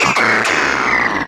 Cri de Quartermac dans Pokémon Soleil et Lune.